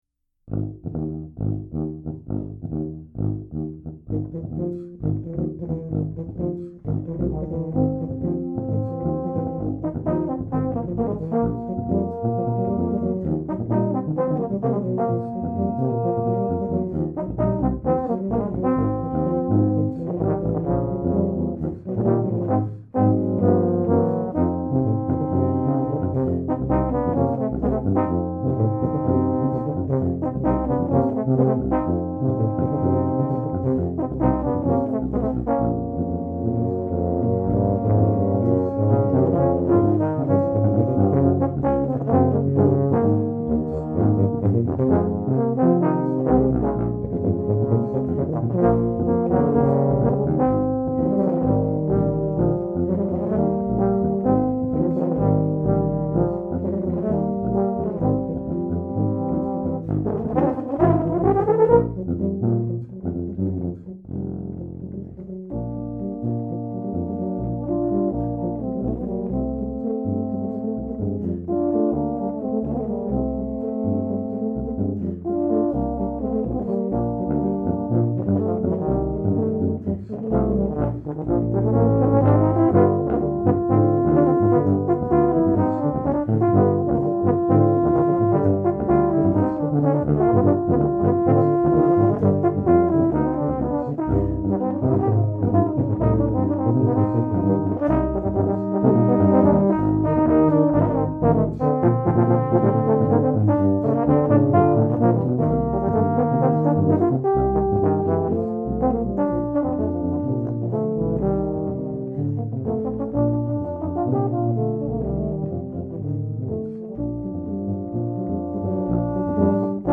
Tuba
The tuba is the most important instrument in the band because it carries the bass line.
tuba1.mp3